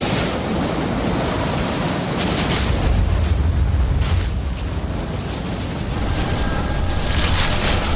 Winds gusting.ogg
Original creative-commons licensed sounds for DJ's and music producers, recorded with high quality studio microphones.
[winds-gusting]_kdw.mp3